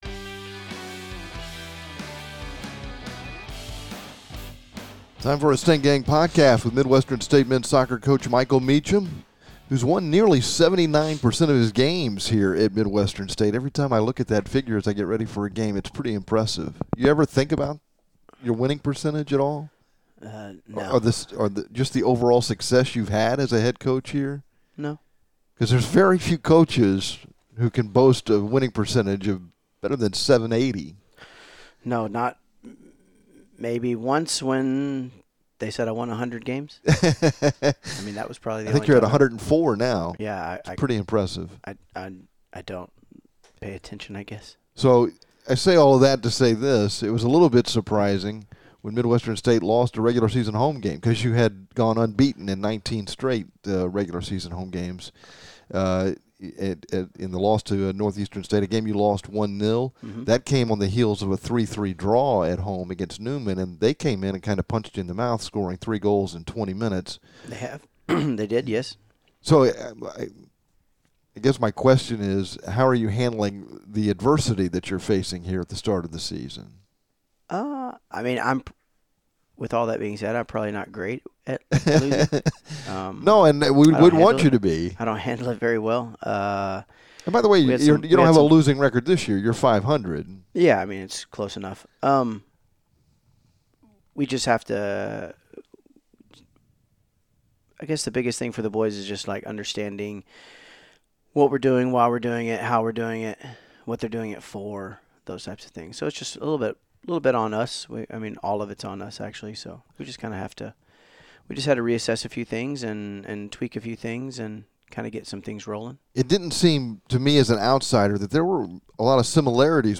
Stang Gang Podcast features complete coverage of Midwestern State Men's Soccer and an interview